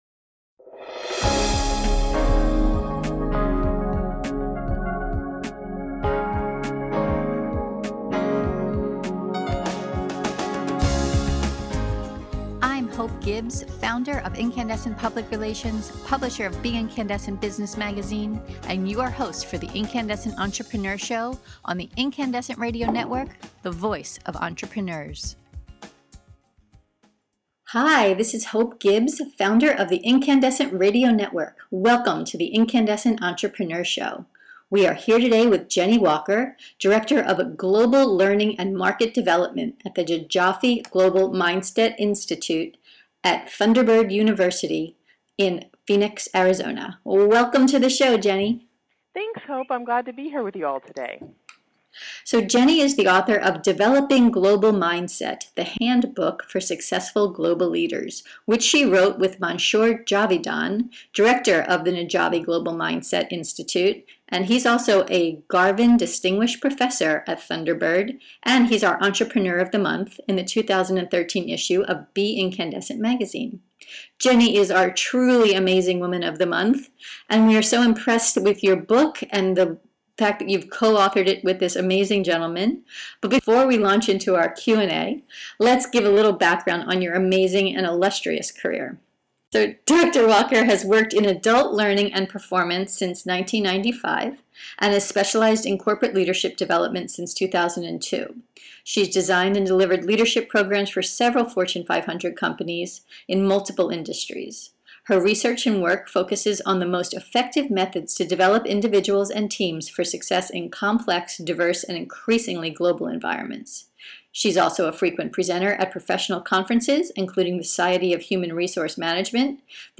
Download our podcast interview now to learn: Why it’s important to develop a global mindset.